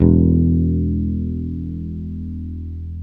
Index of /90_sSampleCDs/Roland L-CD701/BS _Jazz Bass/BS _Warm Jazz